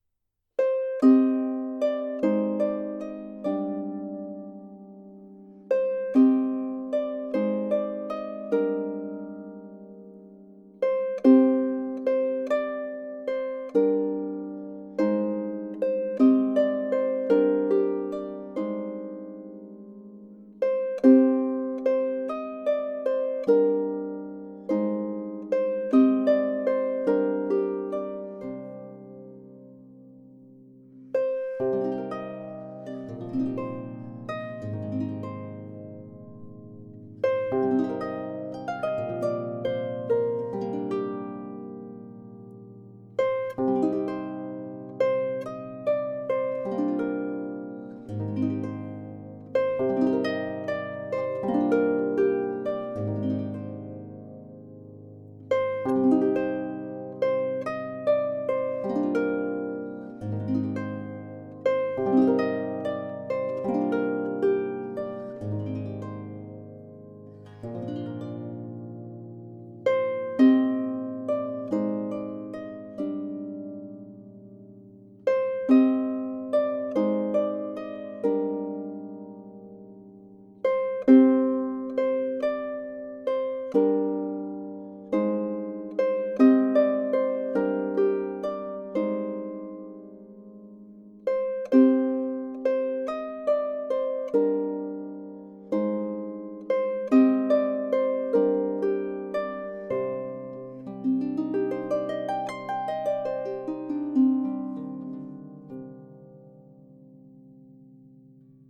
Harfe "Robin"
kraftvoll & schwebend
Die Harfe Robin ist lauter und volltönender als man es auf den ersten Blick aufgrund der zierlichen Erscheinung vermuten würde.